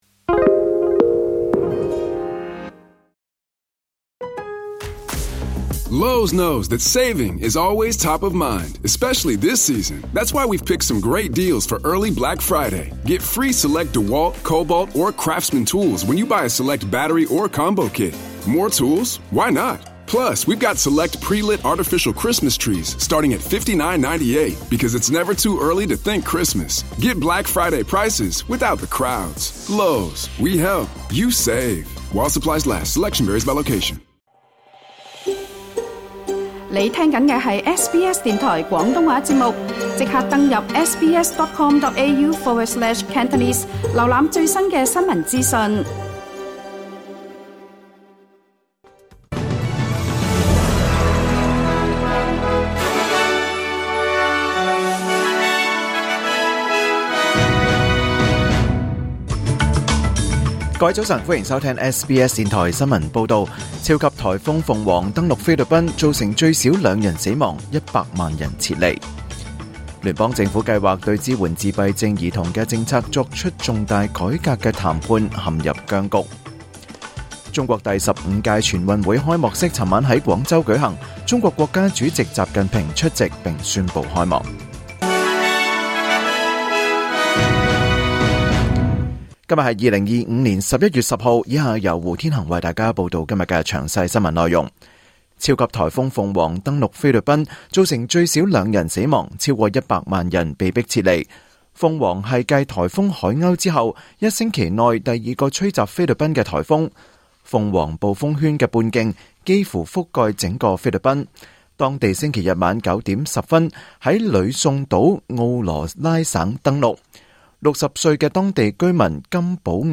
2025年11月10日SBS廣東話節目九點半新聞報道。